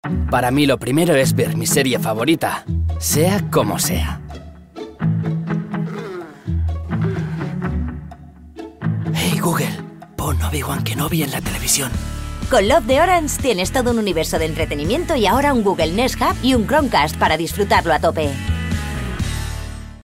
sehr variabel
Jung (18-30)
Eigene Sprecherkabine
Ariel (Singing)
Commercial (Werbung), Comedy